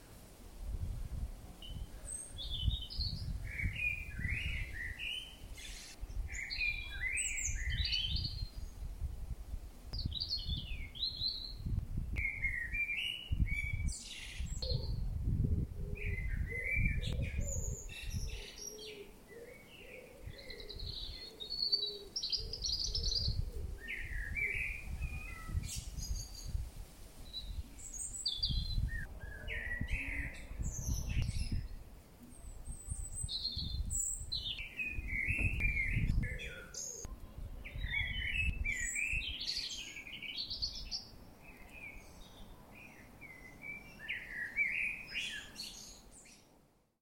But now, to go back to busyness, I have to say that as well as the bees in our garden, our blackbird has been busy too. In no previous year have I heard such long singing so beautifully sung.